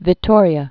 (vĭ-tôrē-ə, -tōryä)